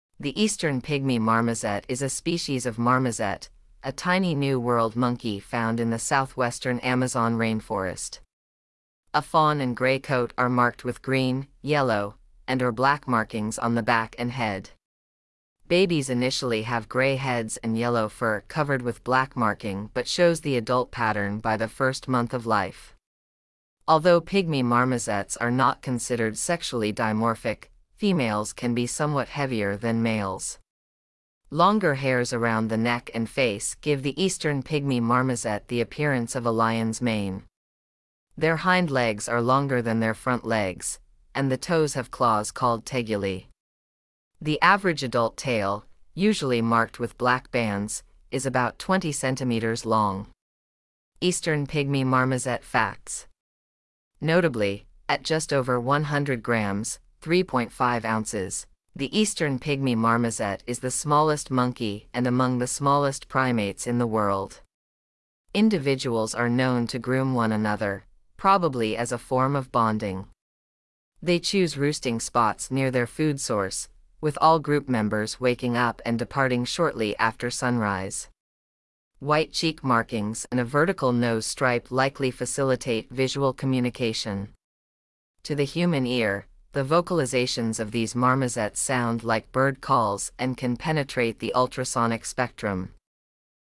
Eastern pygmy marmoset
• To the human ear, the vocalizations of these marmosets sound like bird calls and can penetrate the ultrasonic spectrum.
eastern-pygmy-marmoset.mp3